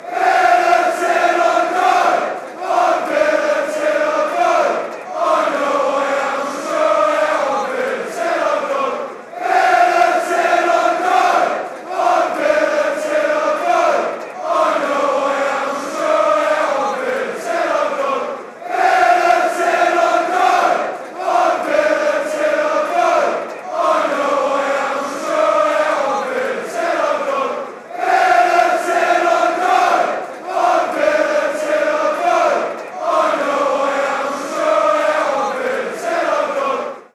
Listen to 218 Aston Villa football songs and Aston Villa soccer chants from Villa Park.
Sang at Bournemouth away